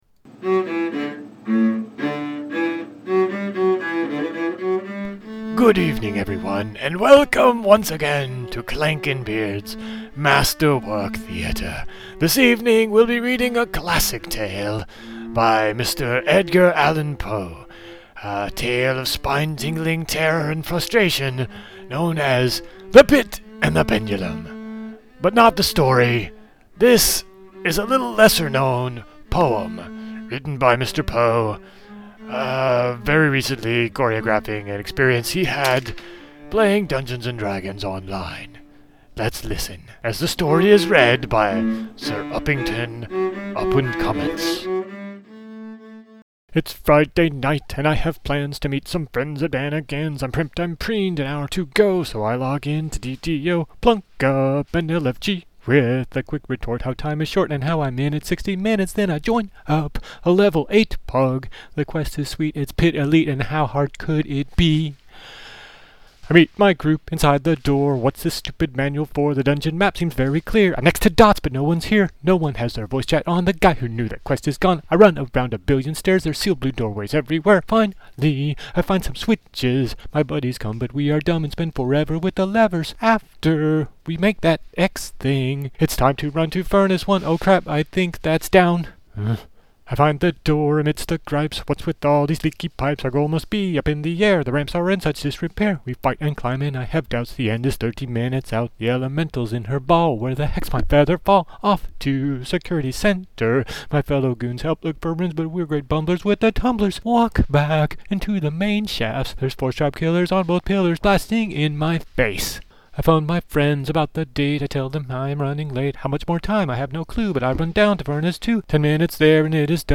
The Pit & The Pendulum. It's like 5 minutes of "The Pit"ty goodness that rhymes (mostly).